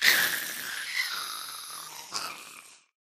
fireball.ogg